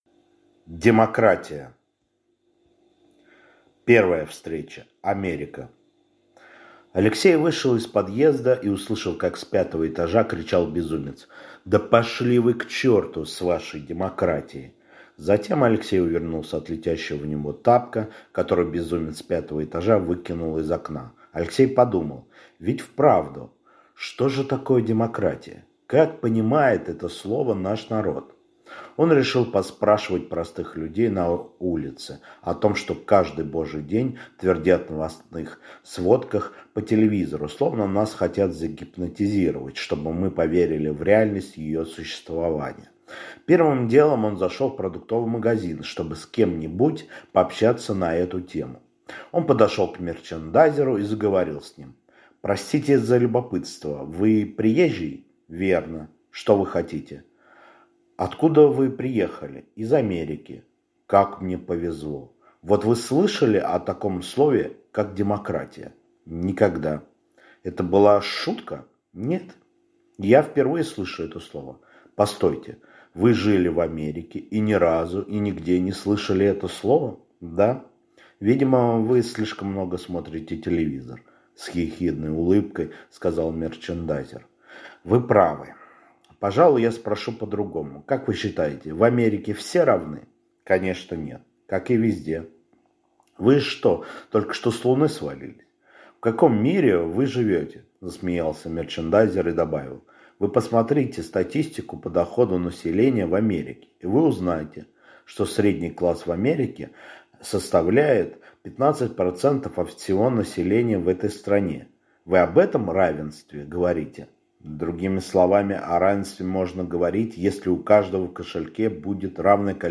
Аудиокнига Демократия | Библиотека аудиокниг